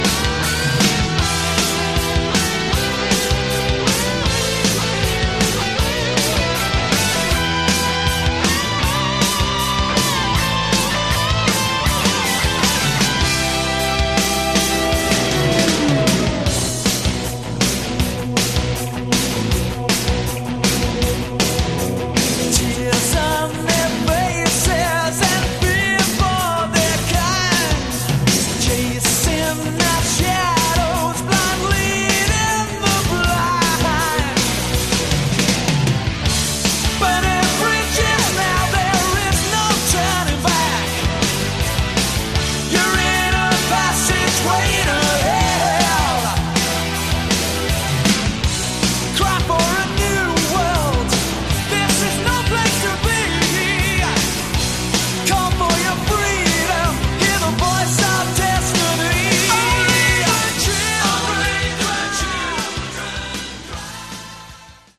Category: Hard Rock
guitars, lead and backing vocals, keyboards
drums, percussion